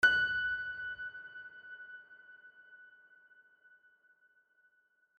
piano-sounds-dev
LoudAndProudPiano